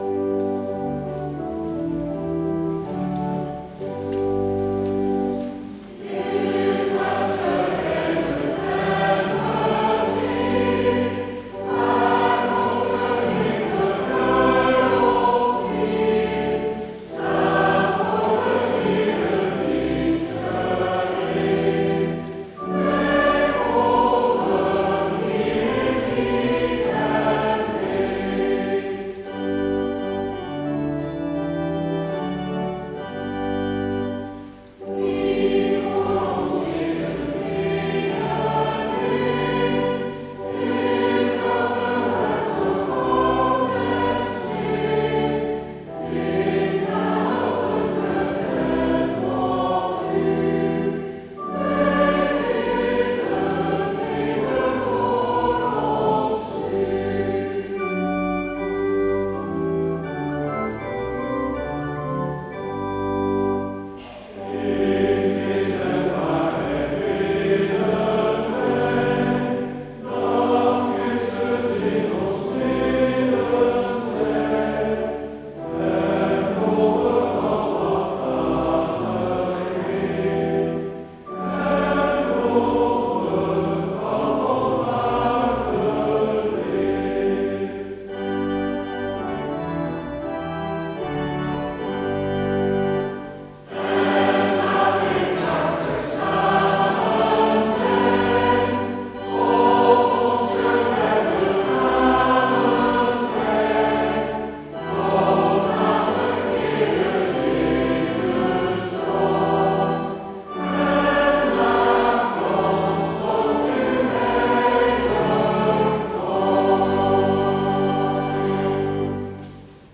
Heropening Sint-Pieterskerk Rotselaar